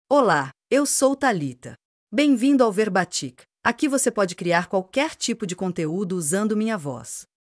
ThalitaFemale Portuguese AI voice
Thalita is a female AI voice for Portuguese (Brazil).
Voice sample
Female
Thalita delivers clear pronunciation with authentic Brazil Portuguese intonation, making your content sound professionally produced.